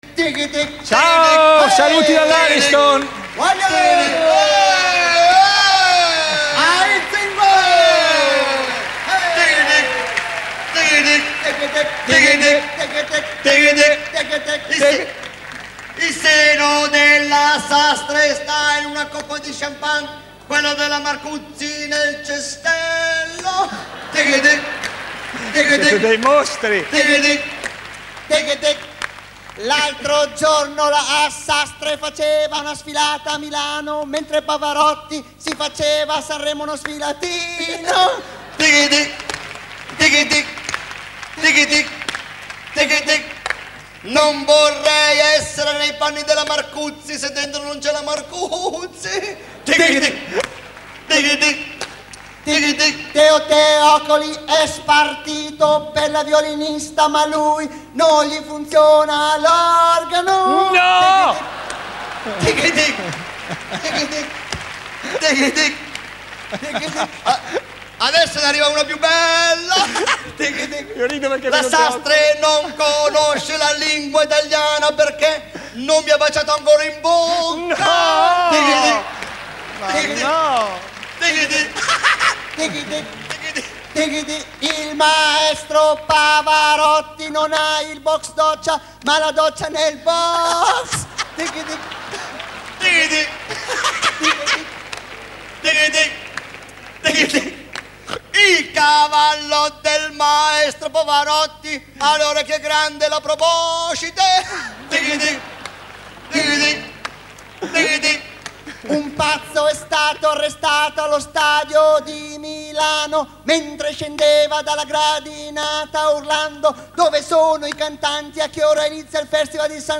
live da sanremo
In occasione del festival (Ed.2000) condotto da Fazio.